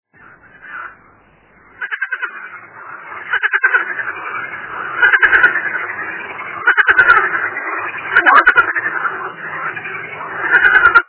The Marsh Frog, known locally as the "laughing frog", is a large species of frog which was introduced to Britain in 1935.
By 1937 they were so widespread that there were numerous complaints to the Ministry of Health about the noise.
Marsh_Frog.mp3